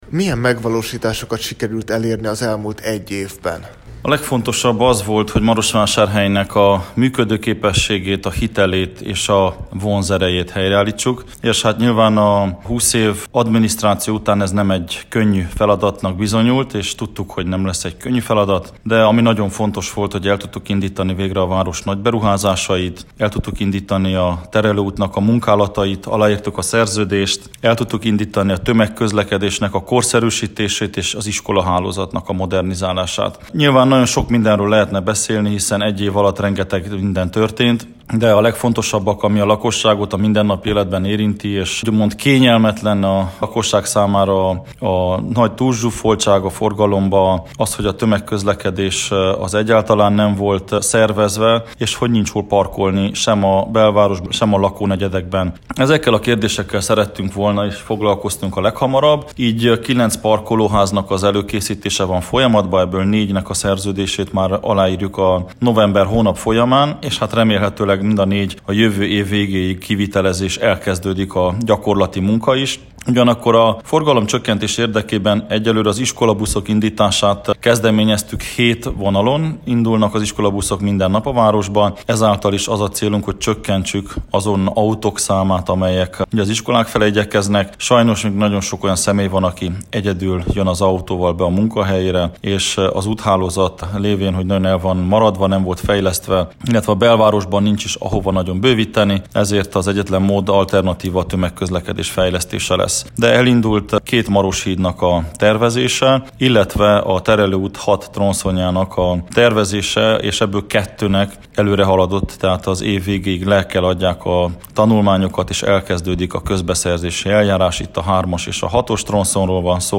Az elmúlt egy évről beszélgettünk Soós Zoltánnal - Marosvasarhelyi Radio